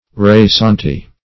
Search Result for " rasante" : The Collaborative International Dictionary of English v.0.48: Rasante \Ra`sante"\ (r[.a]`z[aum]Nt"), a. [F., p. pr. of raser to graze.]